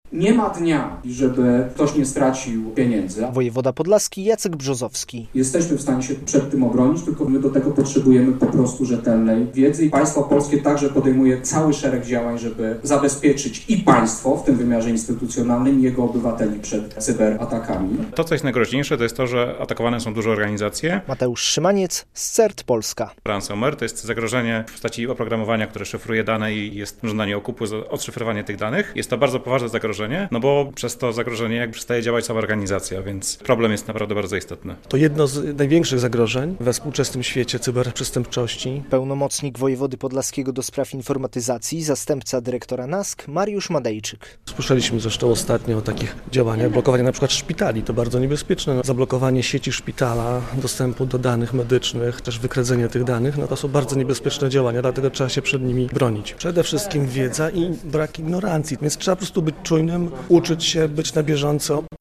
relacja
Zorganizowano tam seminarium poświęcone atakom ransomware.